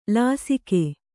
♪ lāsike